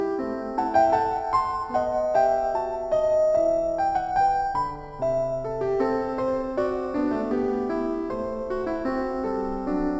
For my dataset, I chose two piano composers of different style, J.S. Bach and F. Schubert.
And here downsampled to 6.3 kHz: